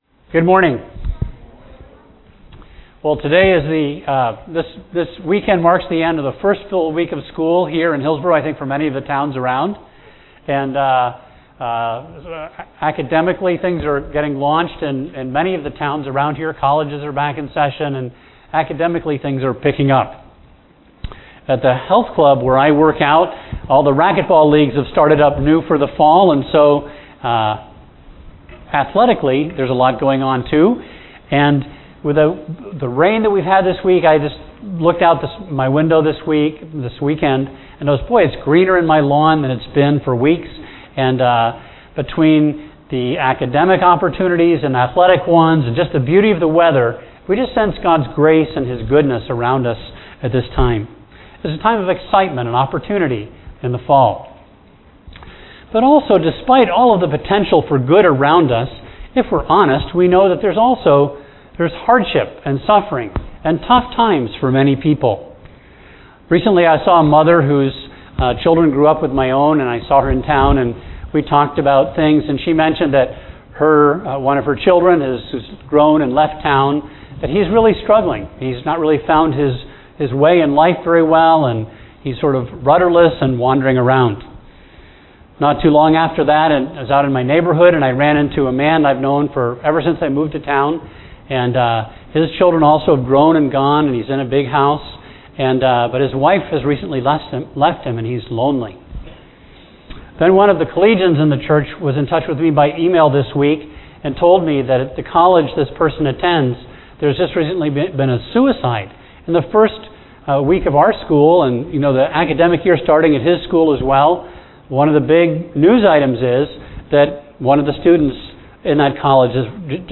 A message from the series "The Acts."